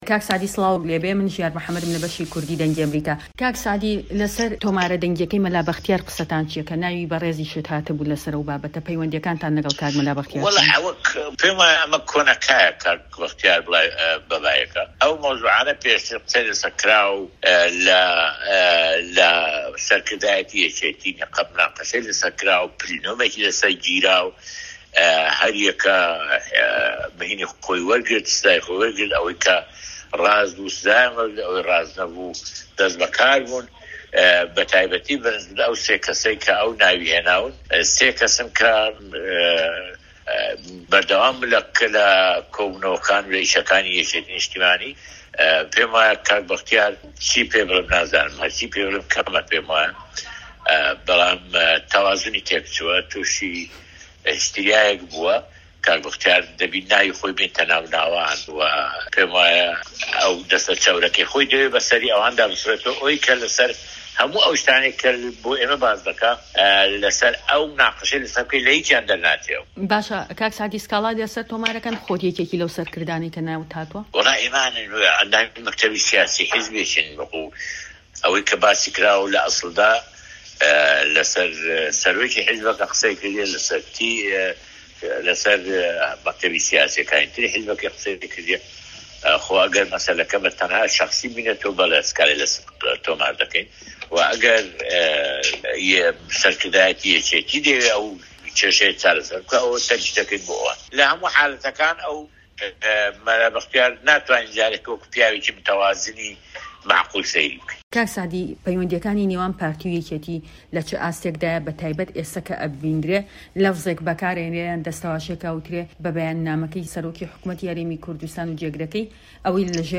وتووێژی سەعدی ئەحمەد پیرە